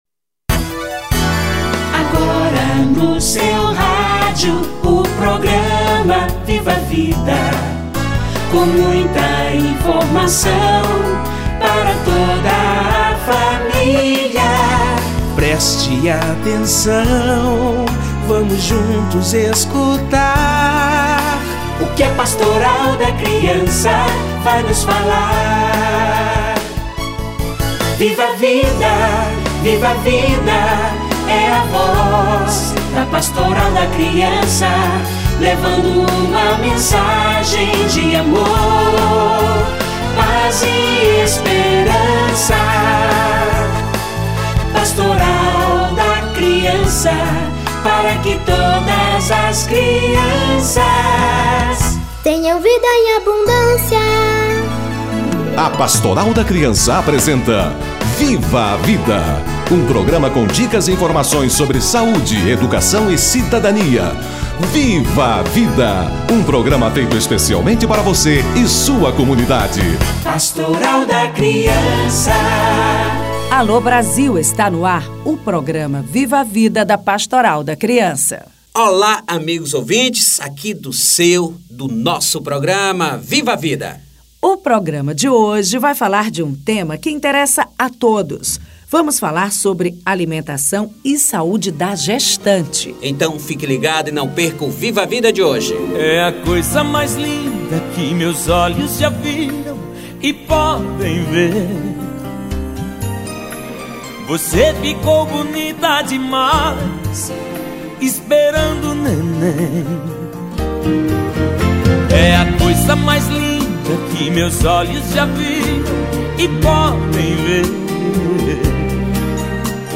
Ganho de peso na gestação - Entrevista